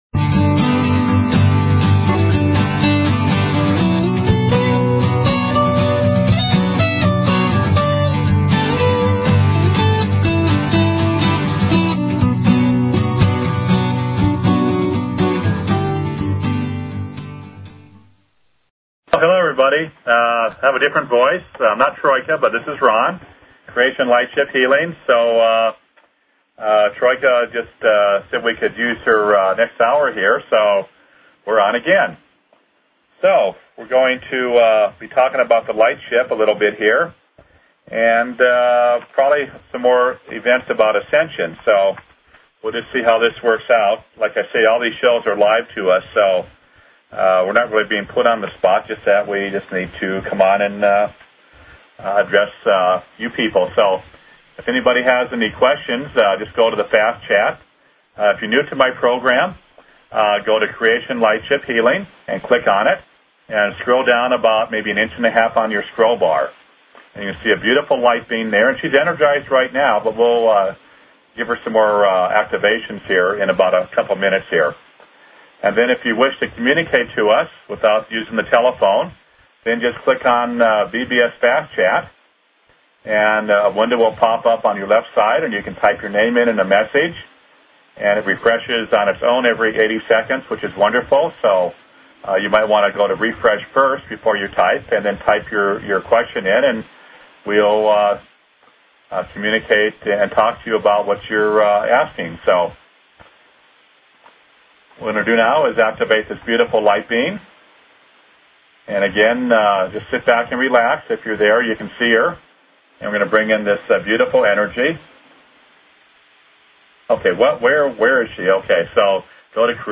Talk Show Episode, Audio Podcast, Ascension_Secrets_of_St_Germain and Courtesy of BBS Radio on , show guests , about , categorized as